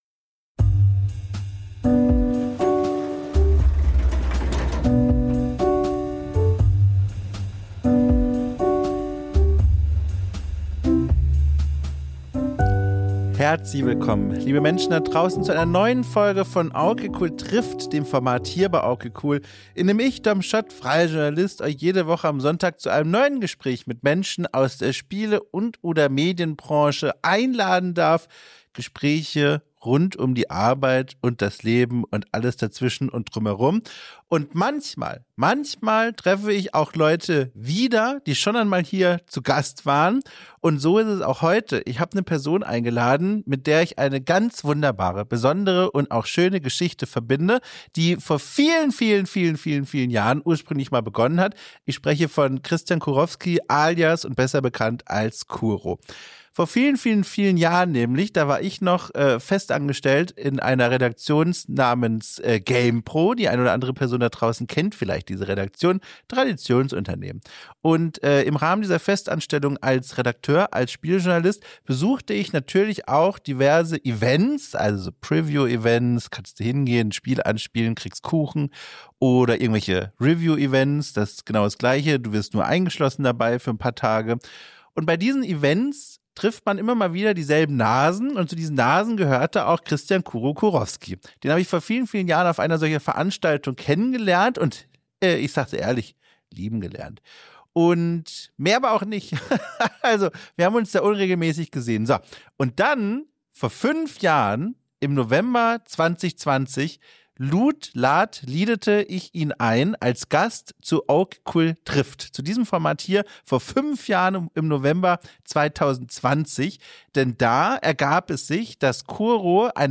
Gespräche über das Leben & die Arbeit drumrum